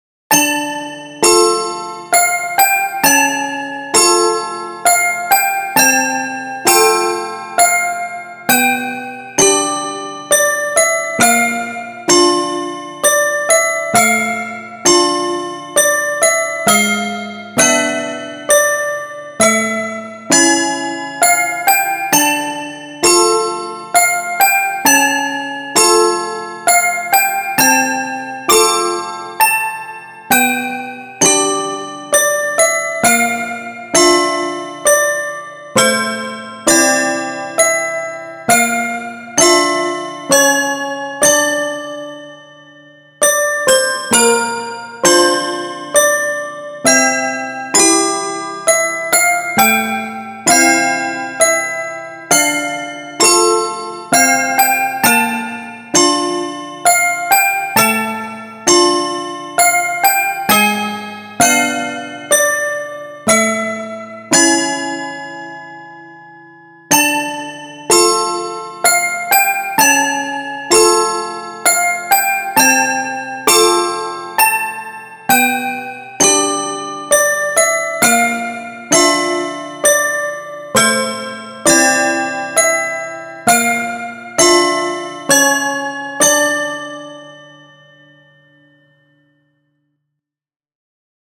⚠公式サイト限定⚠ トイピアノバージョンあり
テンポ ♩=free
トイピアノバージョン ダウンロード